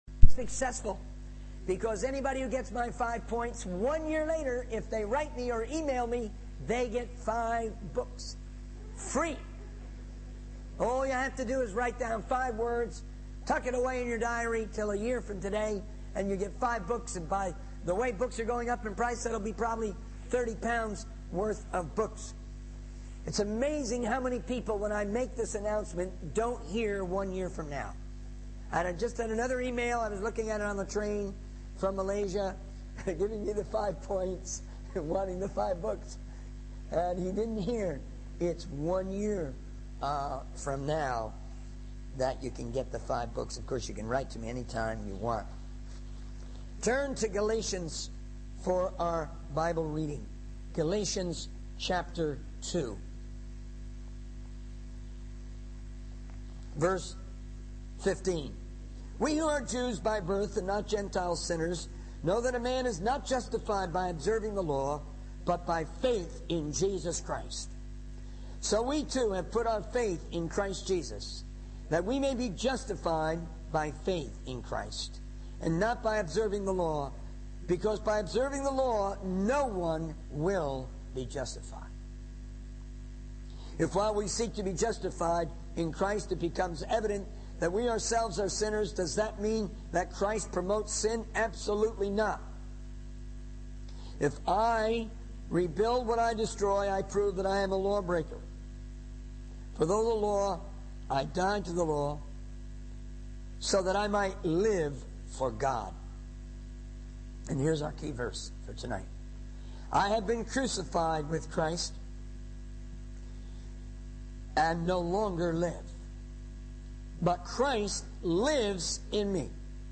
He encourages the congregation to actively engage in sharing the gospel and to maintain a vision for global missions, reminding them that Easter is not just about what Jesus has done for us, but also about how we are called to live for Him. The sermon concludes with a call to action, urging believers to remain steadfast in their faith and to remember that even in difficult times, 'Sunday is coming.'